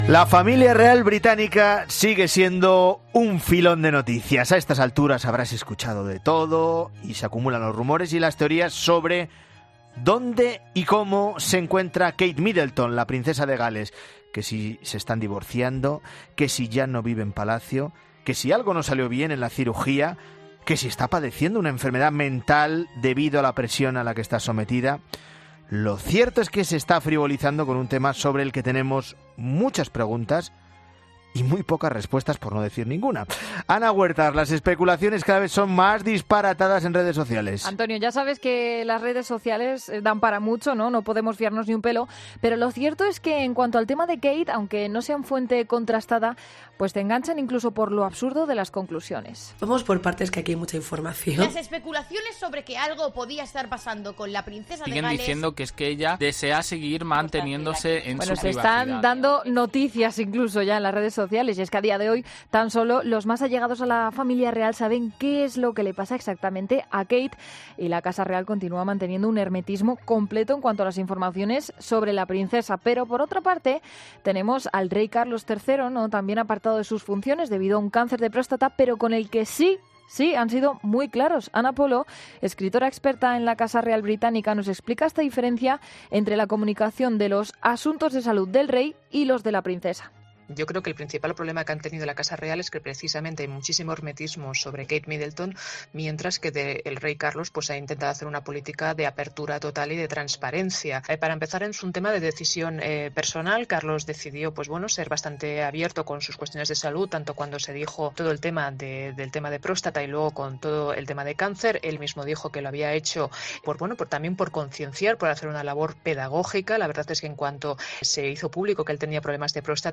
escritora experta en la casa real británica explica en La Mañana Fin de Semana las claves de la crisis provocada por el hermetismo en torno a la princesa de Gales